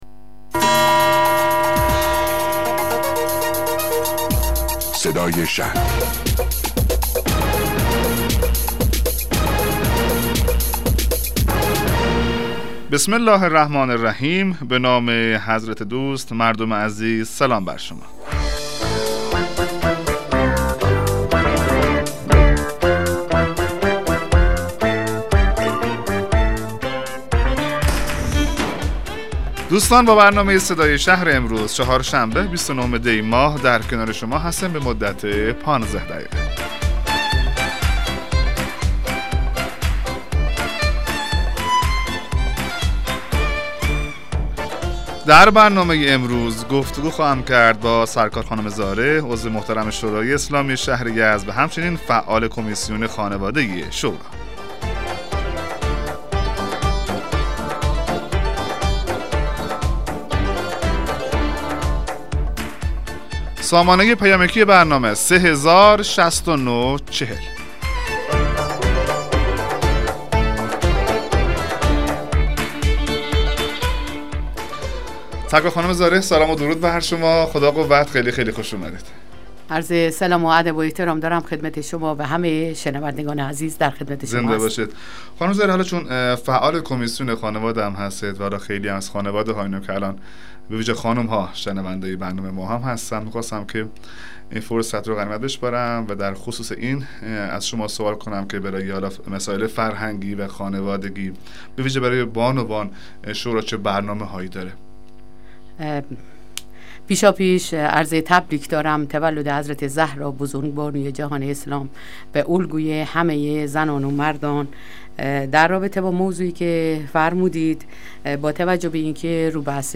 مصاحبه رادیویی برنامه صدای شهر با حضور فاطمه زارع عضو شورای اسلامی شهر یزد